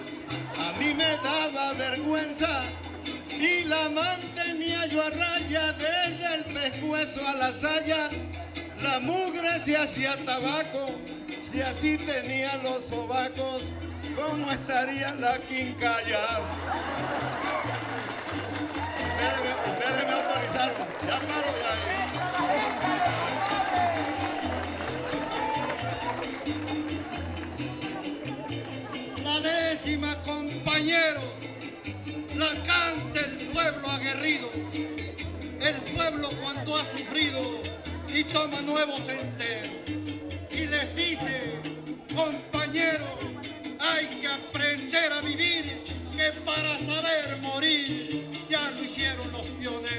• Siquisirí (Grupo musical)
Concurso Estatal de Fandango